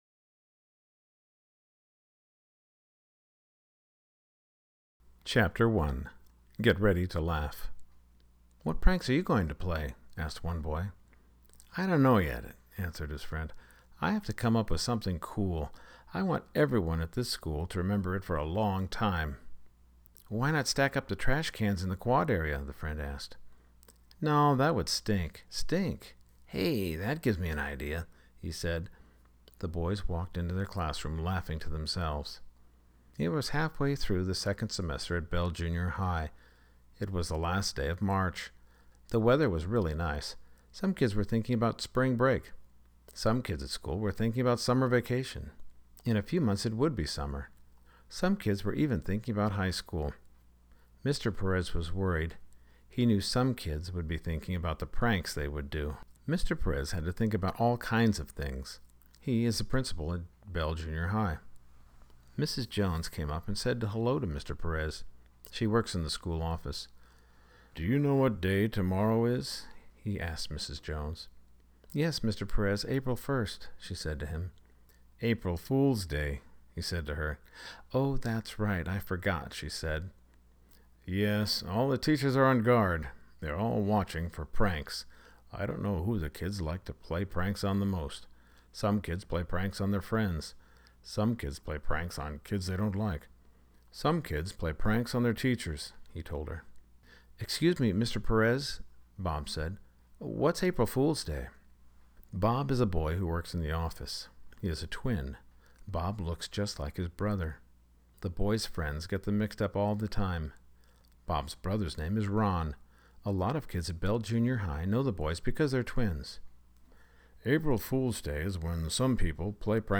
ISBN: N/A (audiobook) English Edition $0.99